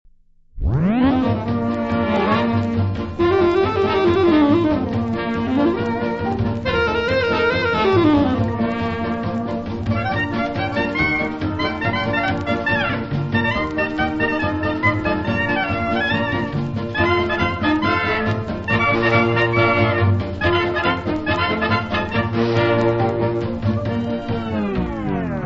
• Coro della Cappella Sistina [interprete]
• Perosi, Lorenzo [direttore d'orchestra]
• mottetti
• musica liturgica
• Móitéit